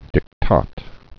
(dĭk-tät)